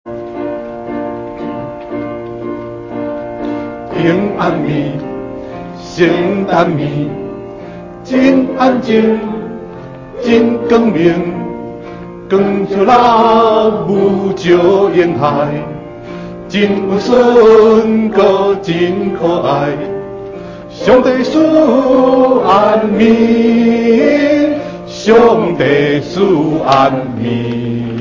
2024聖誕點燈音樂會百人詩班詩歌
平安夜  聖誕夜 (臺語) 快版 不分高低音：